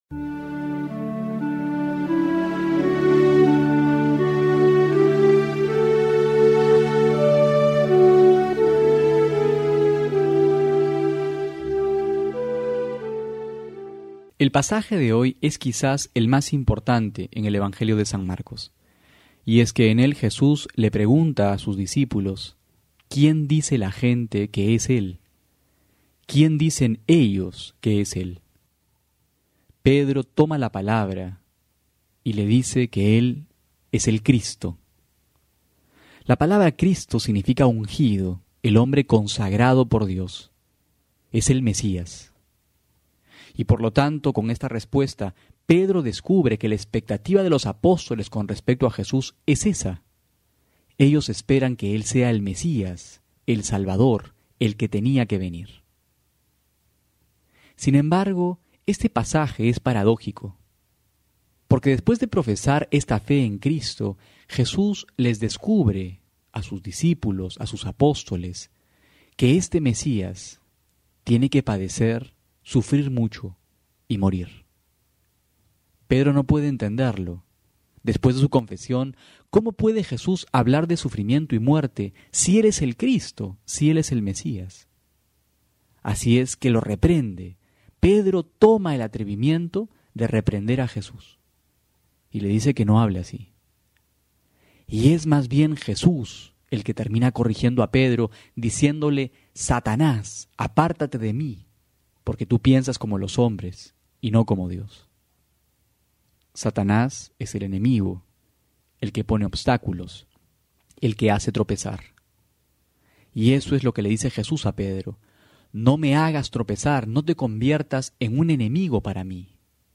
Homilía para hoy: Marcos 8,27-33
febrero16-12homilia.mp3